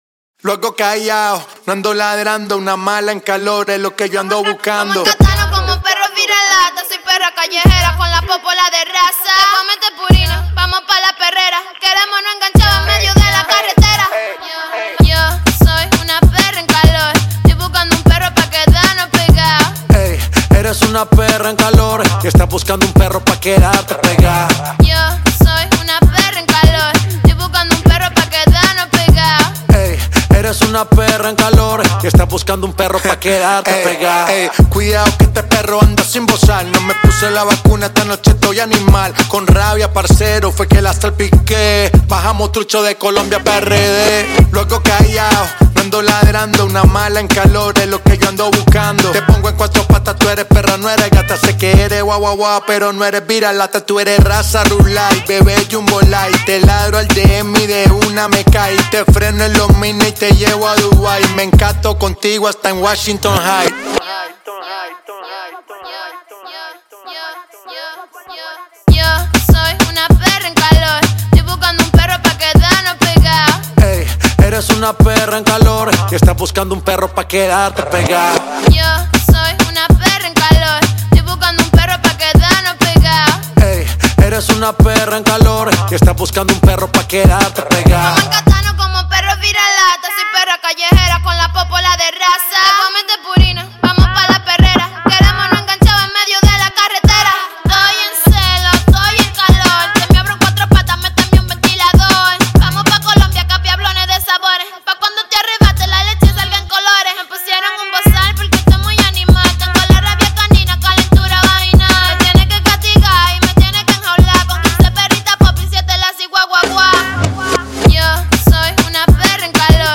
موزیک لاتین